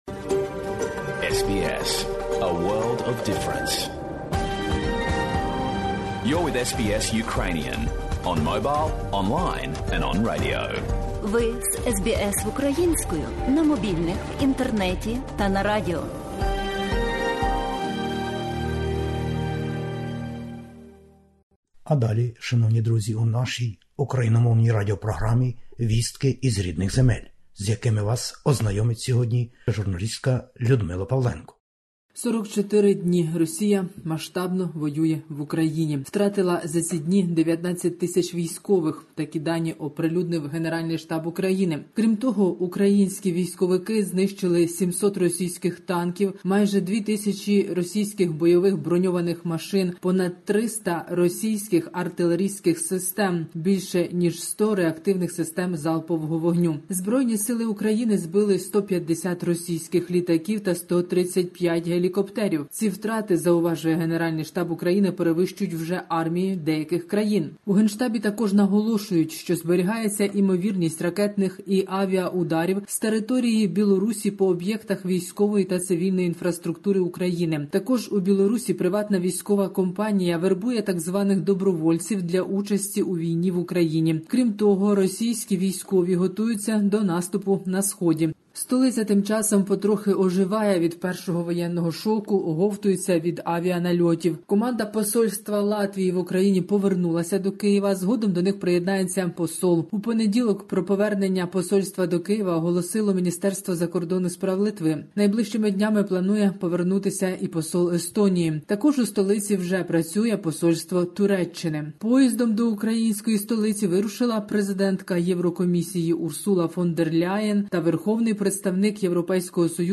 Добірка новин із воюючої України. 44-ий день з початку широкомасштабного вторгнення російських збройних сил.